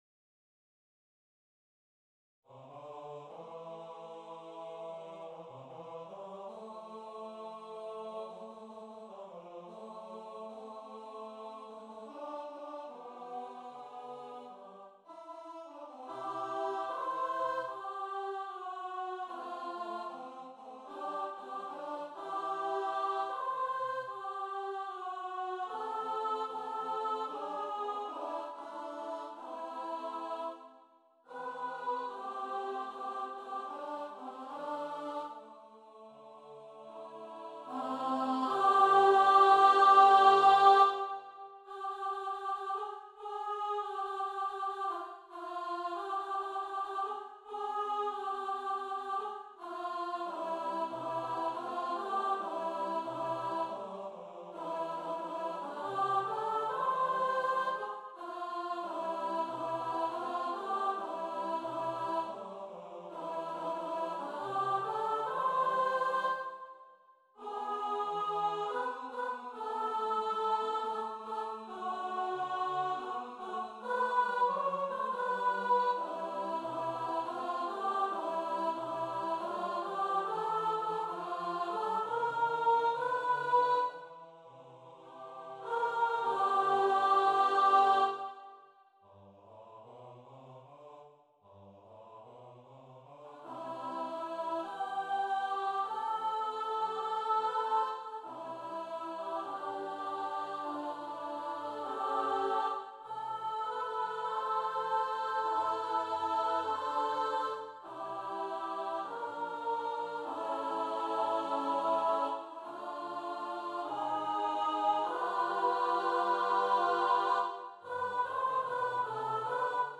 TV-Theme-Medley-Sop | Ipswich Hospital Community Choir
TV-Theme-Medley-Sop.mp3